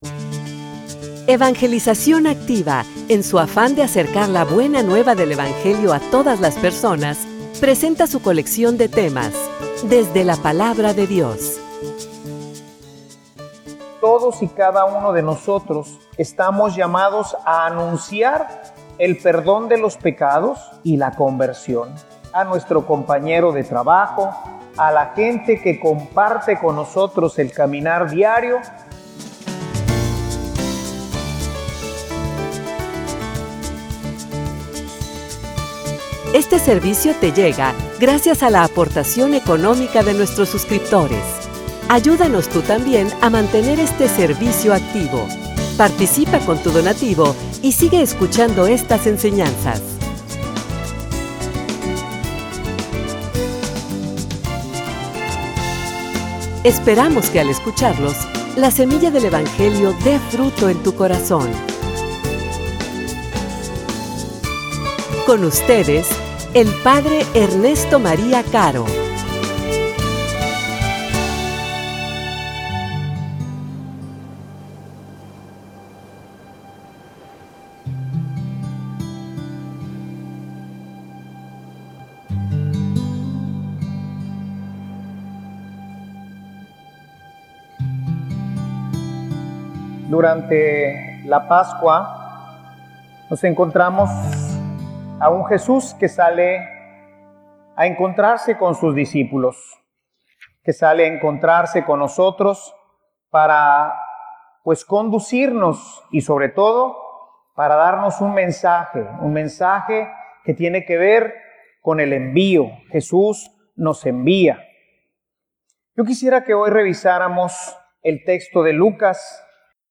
homilia_El_mensaje.mp3